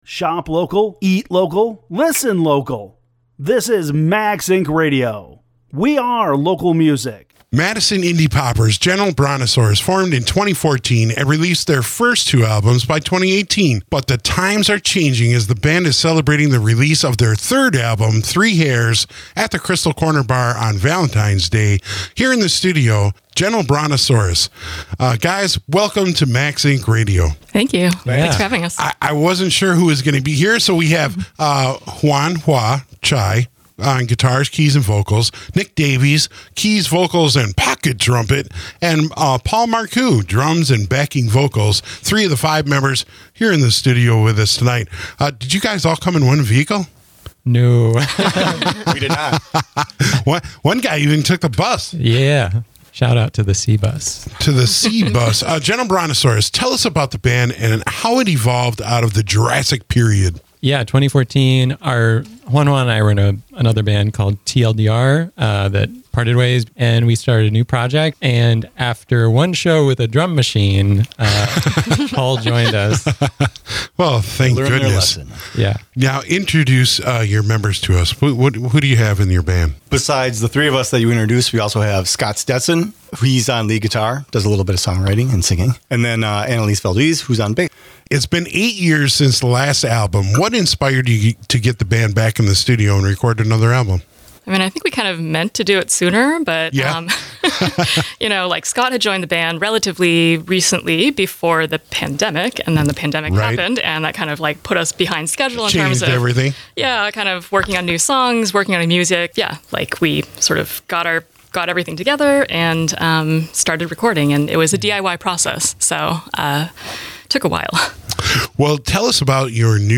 The band comes to the studio to chat on Max Ink Radio.